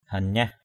/ha-ɲah/